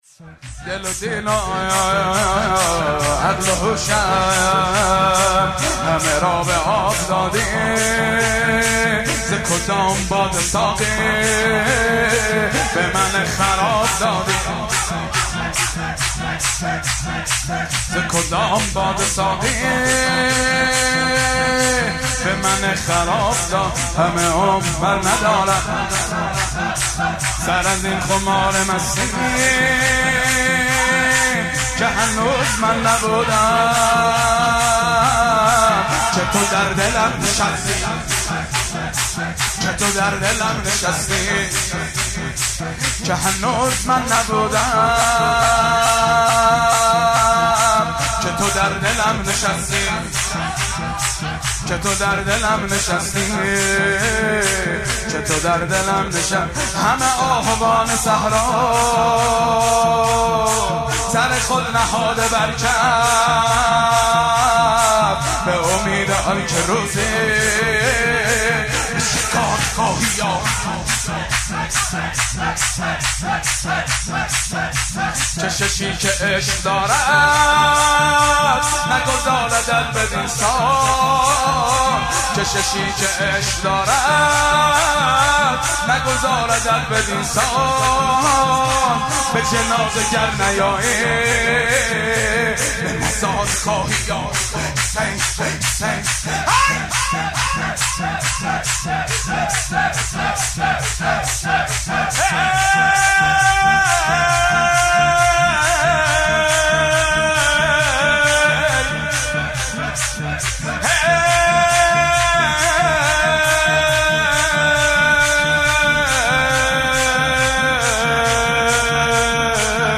مناسبت : دهه اول صفر
قالب : شور